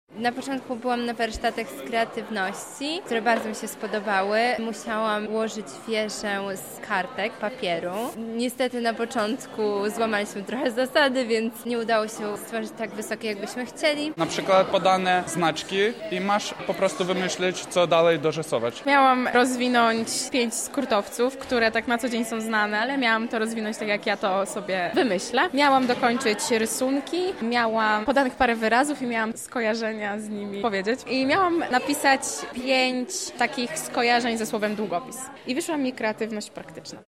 Studenci oraz młodzież rozruszała dziś swoją wyobraźnię – wzięliśmy udział w Dniu Kreatywności UMCS